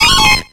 Cri de Farfuret dans Pokémon X et Y.